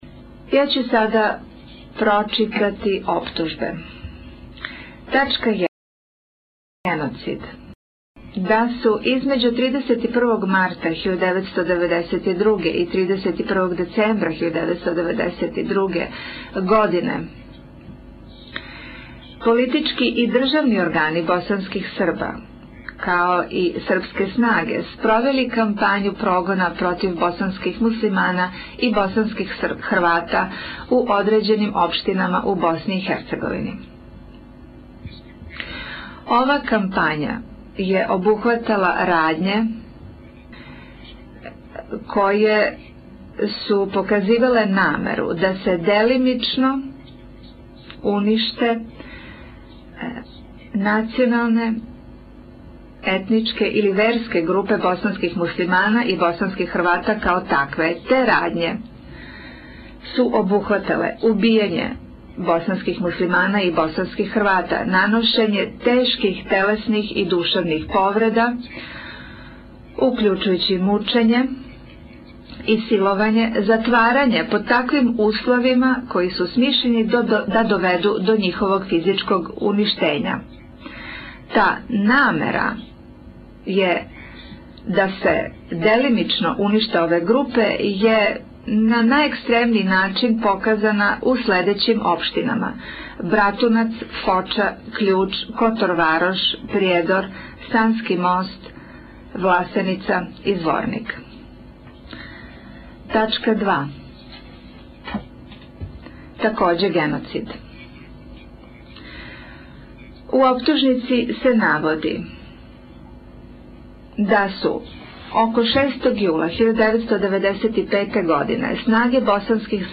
Sudac Ori čita sažetak optužnice